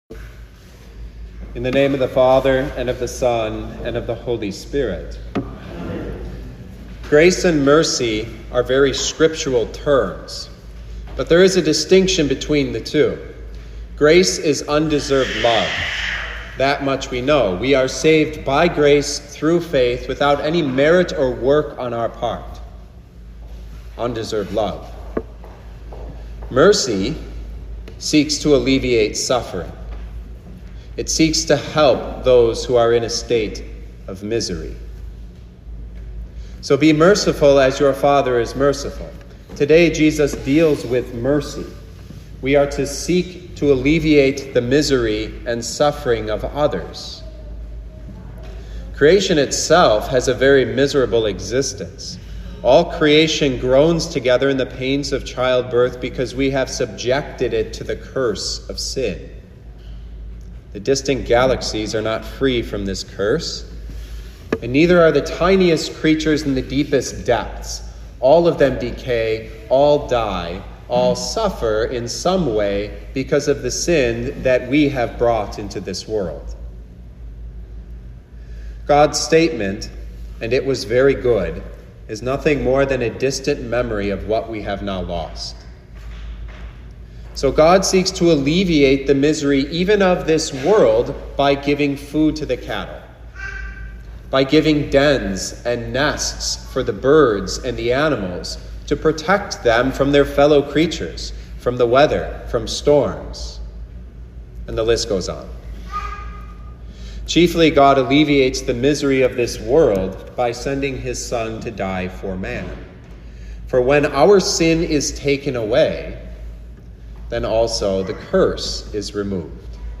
2024 Pentecost 5 Preacher